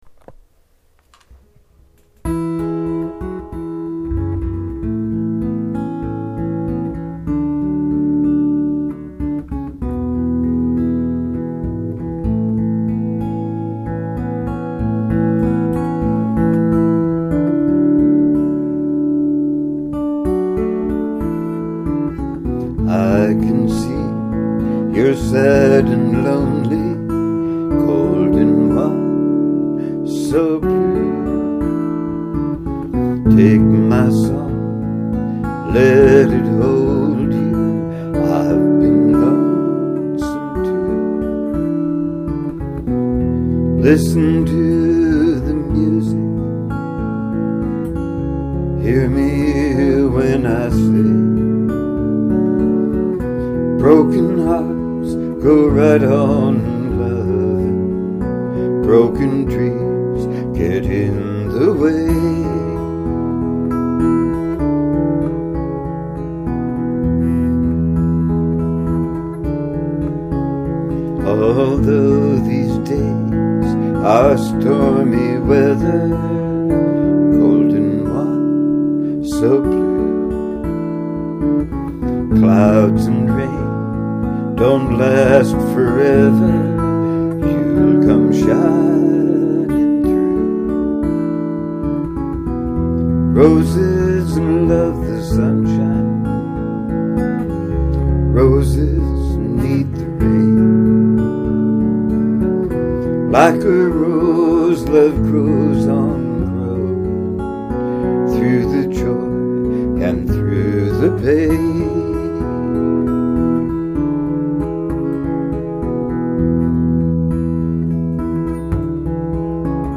mostly just me and my guitars.